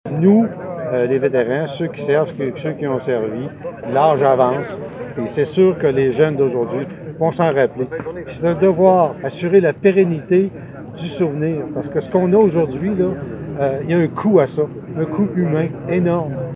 Des anciens combattants, des élus municipaux et une vingtaine d’élèves de l’école primaire Harfang des neiges étaient réunis hier au Cénotaphe, devant l’Hôtel de Ville de Bécancour pour commémorer cette journée.
Les invités ont pris le micro pour rendre hommage aux soldats bécancourois tombés pendant les deux Guerres mondiales.